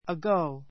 ago 中 A1 əɡóu ア ゴ ウ 副詞 （ ⦣ 比較変化なし） （今から） ～前に ⦣ 今からみて時間的にどれくらい前なのかを表す.